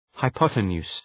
Προφορά
{haı’pɒtə,nu:s} (Ουσιαστικό) ● υποτείνουσα